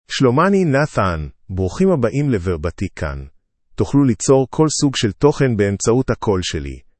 Nathan — Male Hebrew (Israel) AI Voice | TTS, Voice Cloning & Video | Verbatik AI
MaleHebrew (Israel)
Nathan is a male AI voice for Hebrew (Israel).
Voice sample
Listen to Nathan's male Hebrew voice.
Nathan delivers clear pronunciation with authentic Israel Hebrew intonation, making your content sound professionally produced.